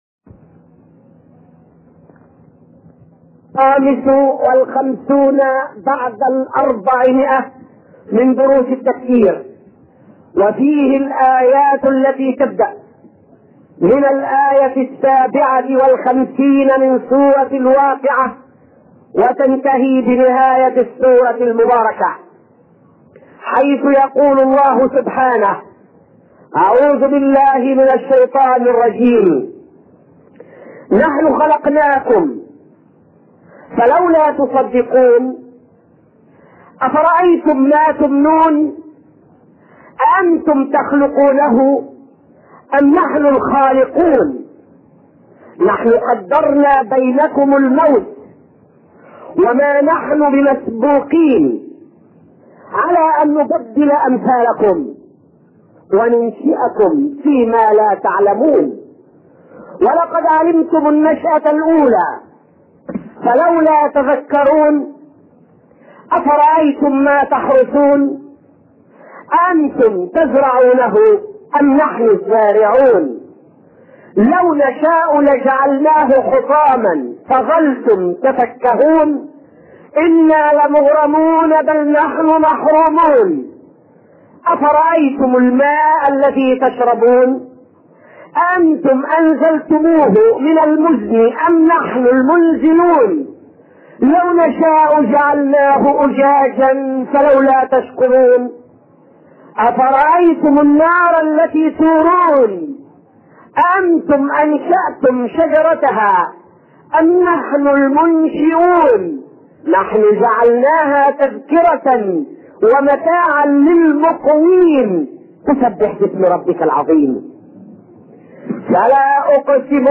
تفسير سورة الواقعة الدرس الأول من الآية 1إلى الآية 56